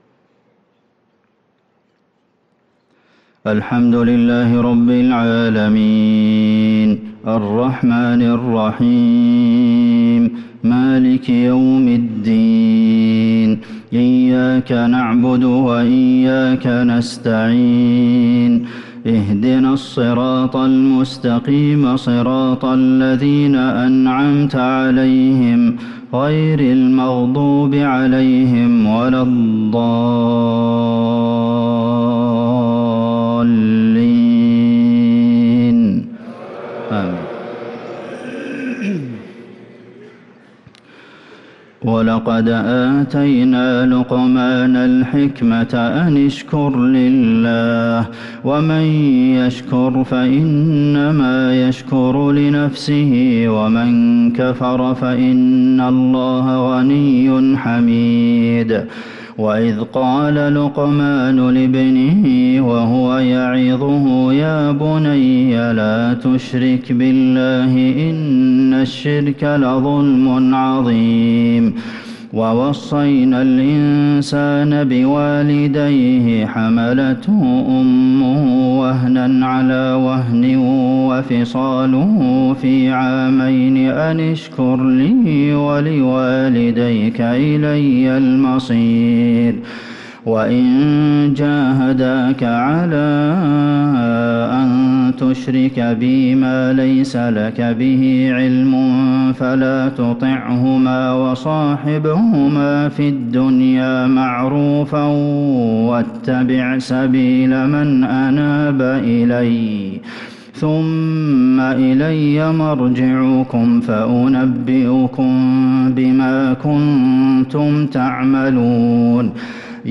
صلاة العشاء للقارئ عبدالمحسن القاسم 14 محرم 1445 هـ